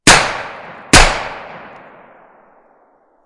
毛瑟枪声
描述：经典的9毫米毛瑟枪声。
标签： 手枪 老旧 驳壳枪 枪击 巨响
声道立体声